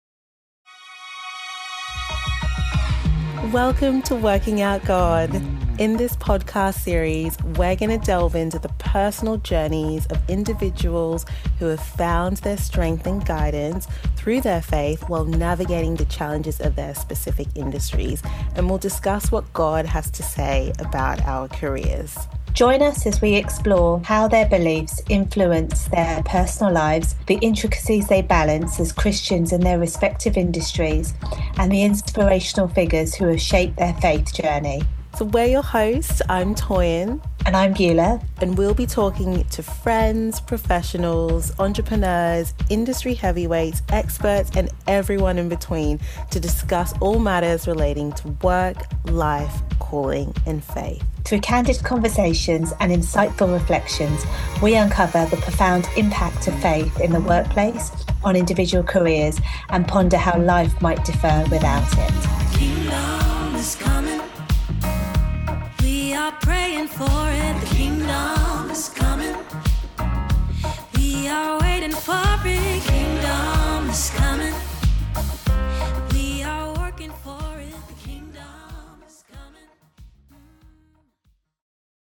Music by The Porters Gate